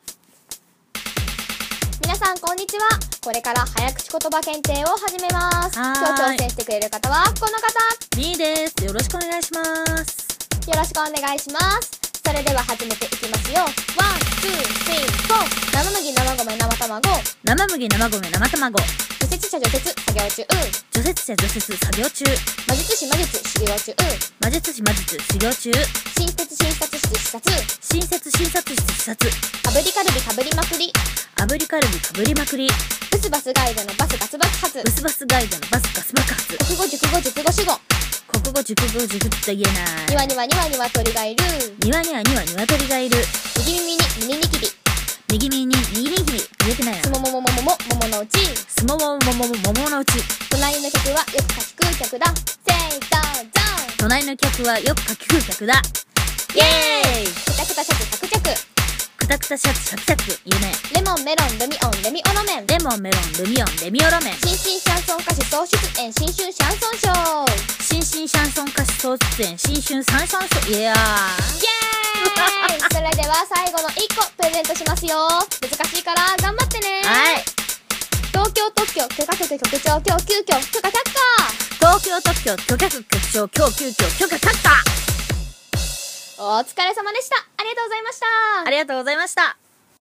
【声面接】早口言葉検定！【言えるかな？】